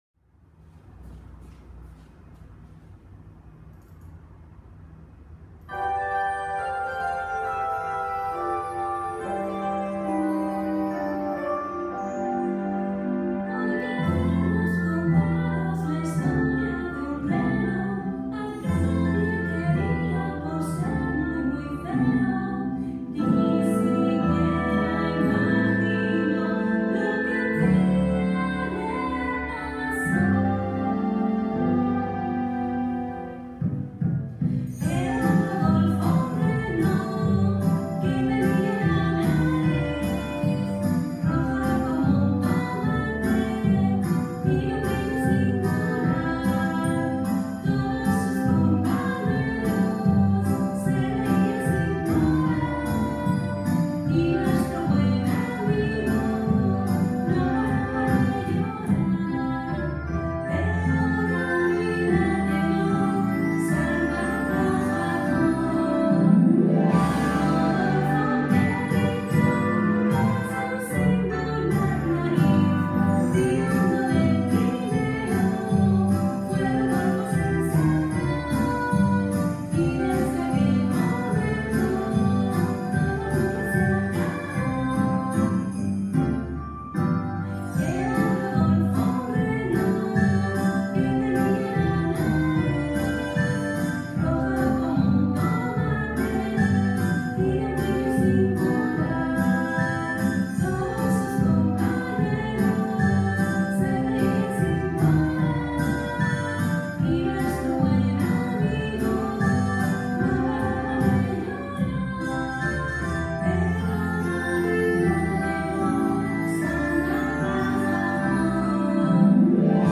Con Voz